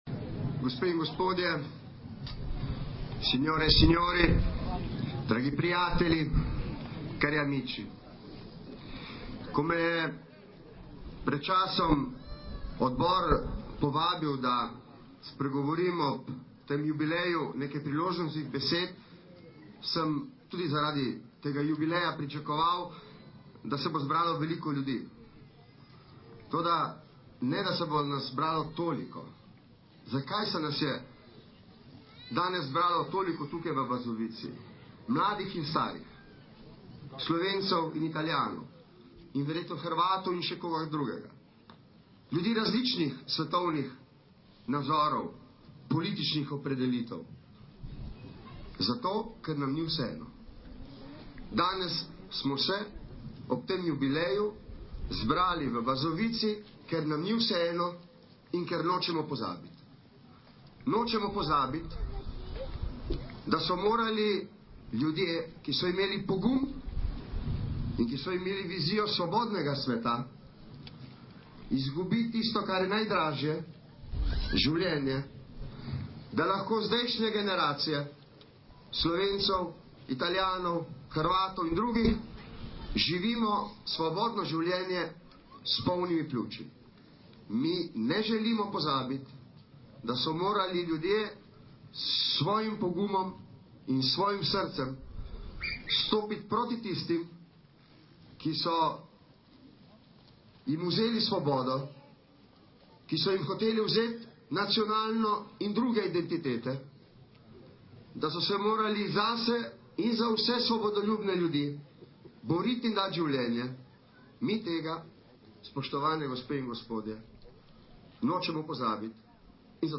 Govor predsednika Vlade RS Boruta Pahorja na osrednji spominski svečanosti ob 80-letnici ustrelitve bazoviških junakov